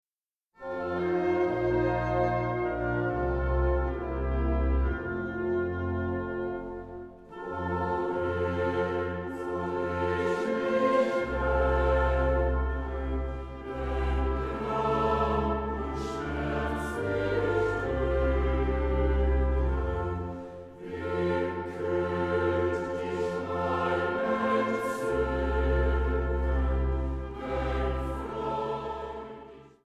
Feierstunde in Berlin-Lichtenberg am 6. November 2010